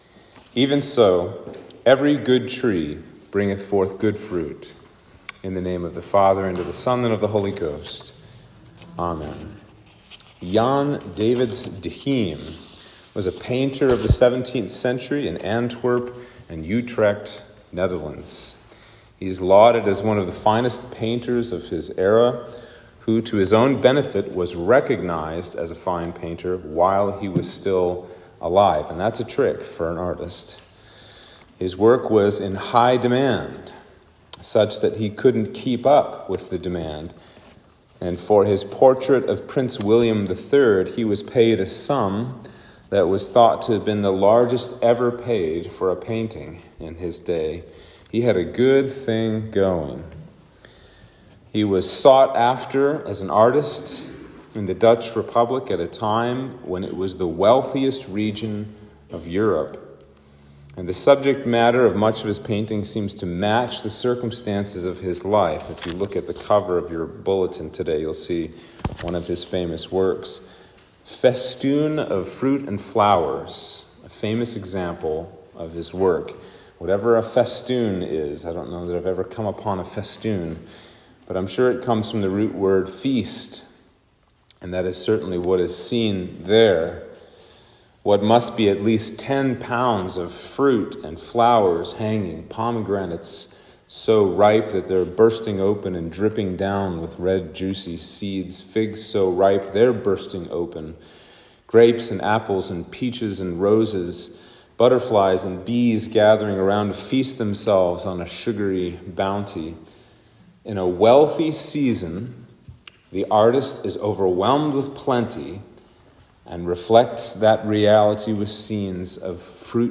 Sermon for Trinity 8